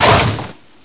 1 channel
mission_sound_droidhit01.wav